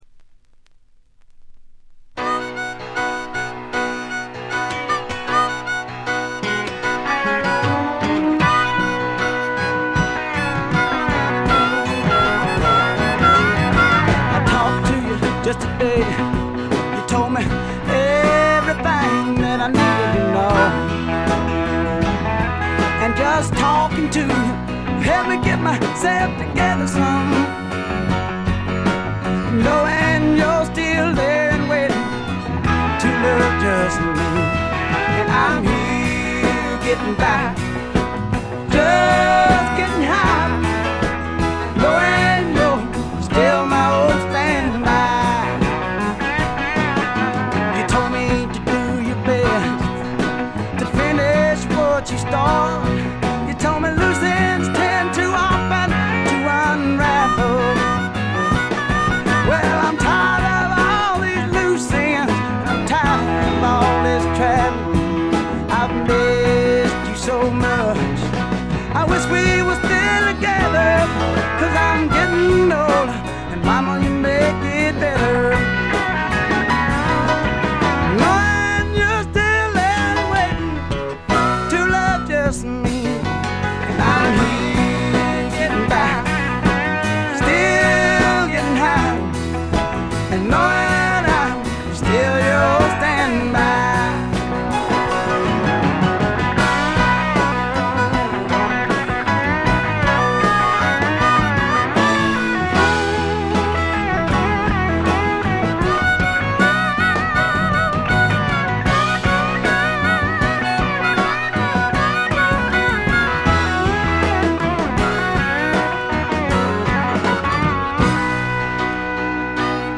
これ以外は軽微なバックグラウンドノイズ程度。
びしっと決まった硬派なスワンプ・ロックを聴かせます。
試聴曲は現品からの取り込み音源です。
Recorded at Paramount Recording Studio.